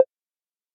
tap5.ogg